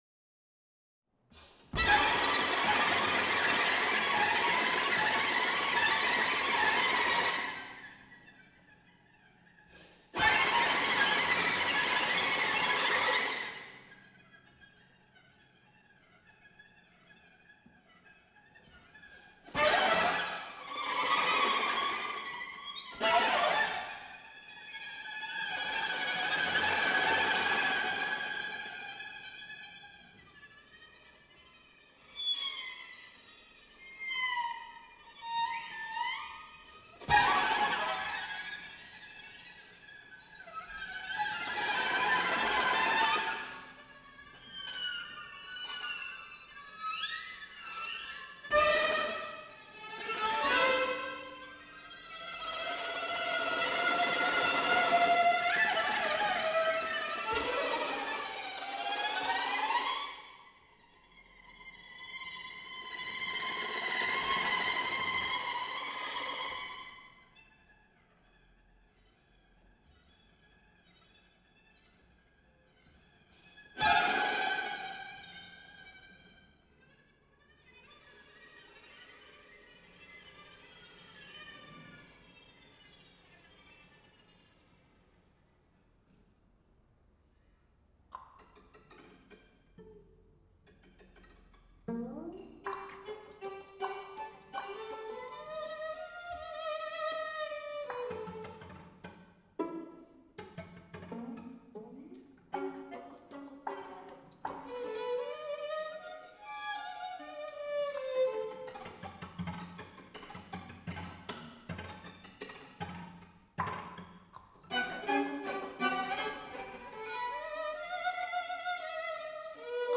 Venue: St. Brendan’s Church
Instrumentation: 2vn, va, vc
String Quartet
violins
viola
cello